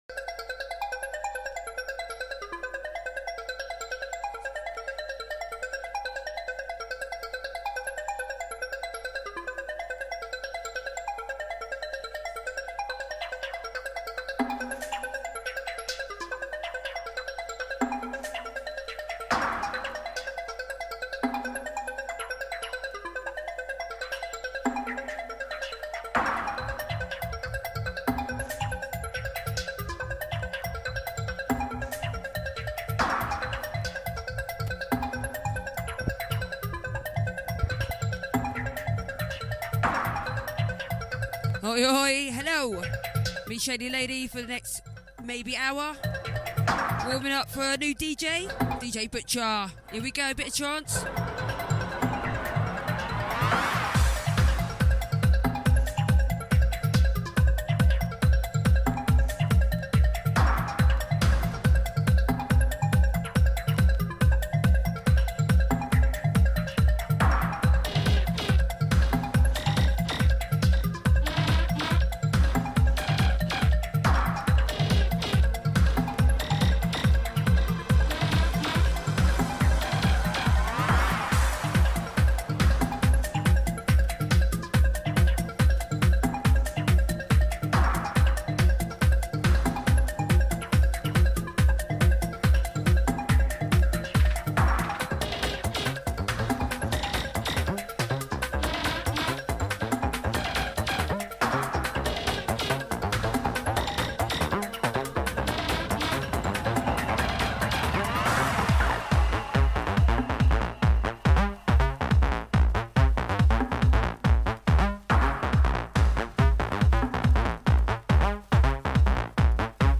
start with trance progress to hardstyle!
Trance Hardstyle Hard House